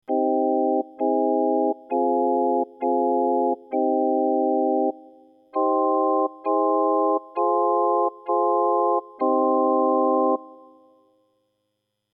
A short sequence of beatless chords: I-min, I-xen-augmin, IV-min, IV-min, I-min
Audio2_tonal_space_I-min+I-augmin+IV-min.mp3